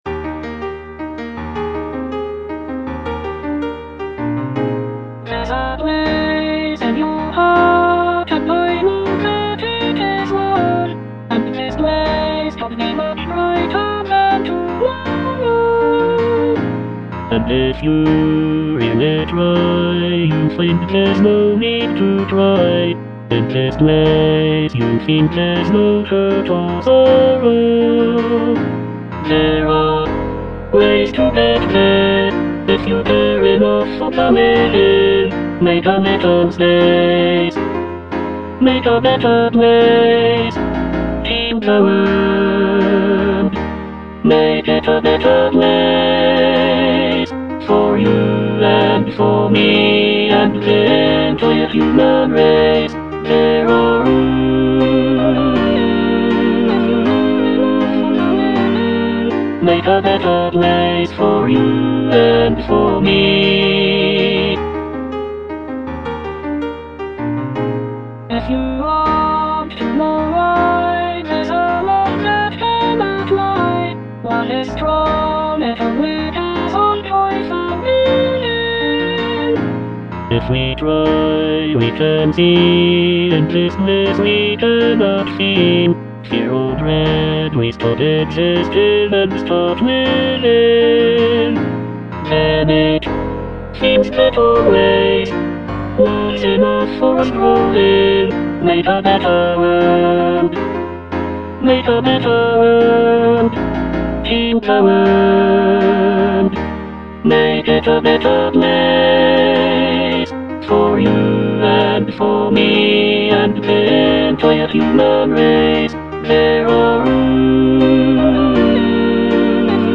Tenor (Emphasised voice and other voices)